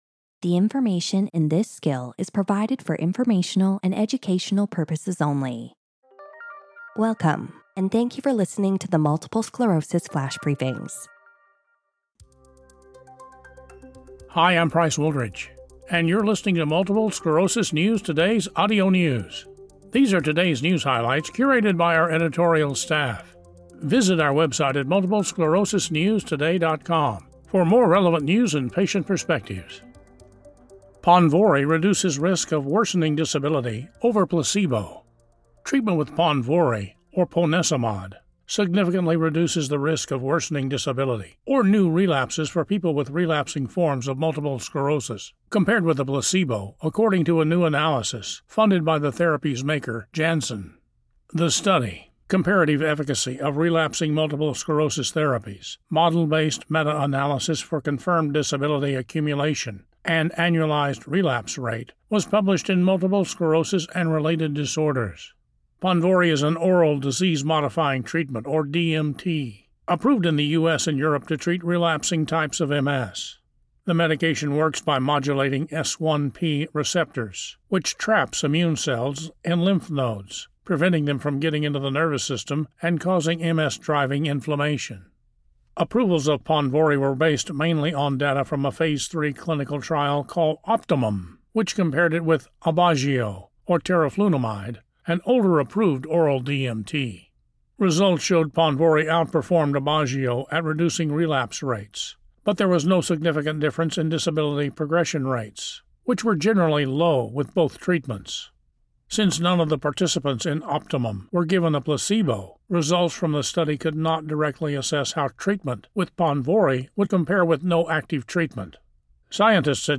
reads a news article on the effectiveness of Ponvory that has emerged from analyzing a database of multiple sclerosis clinical trials compiled by Certara.